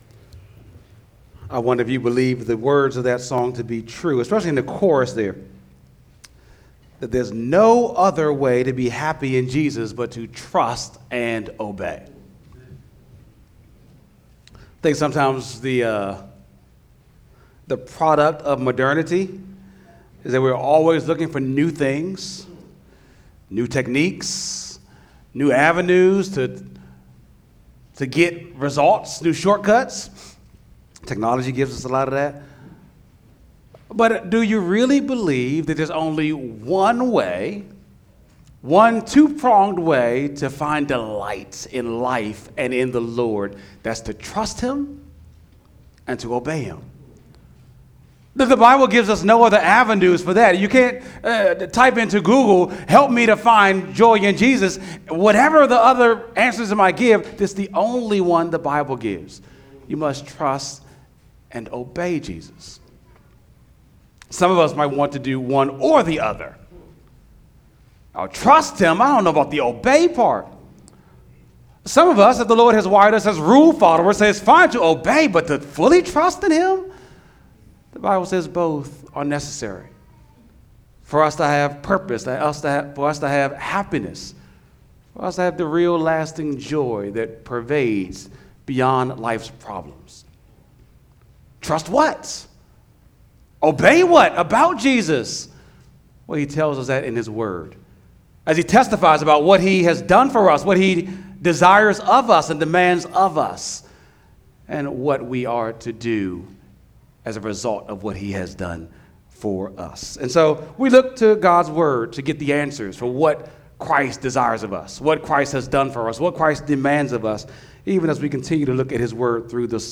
sunday-sermon-6-29-25.mp3